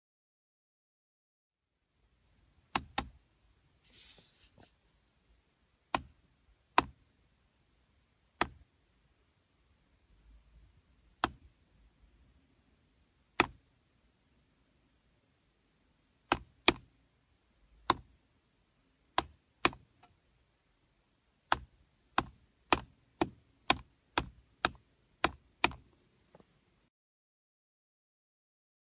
016 敲打闹钟
描述：Blumlein立体声（MKH 30）用AETA 4Minx录制在巴黎公寓内，进行编辑（创建点击次数）
标签： 塑料 命中 对象
声道立体声